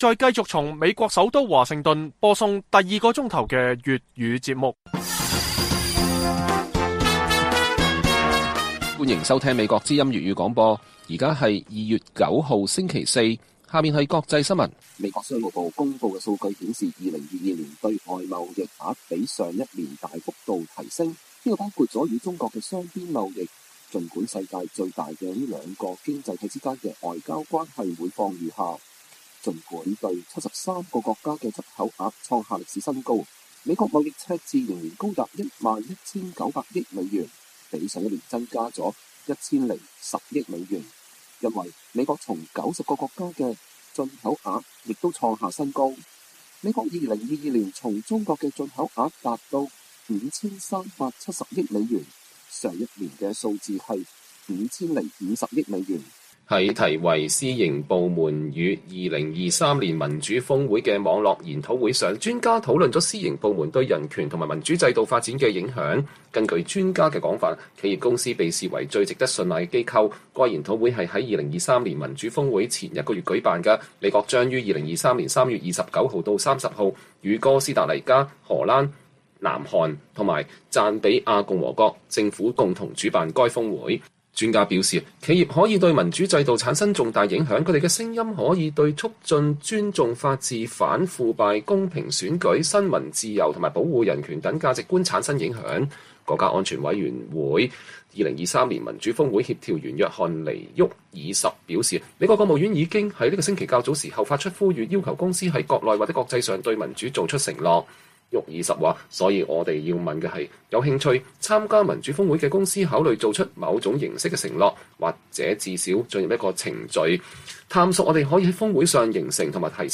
粵語新聞 晚上10-11點: 美國2022年對華貿易赤字高達3830億美元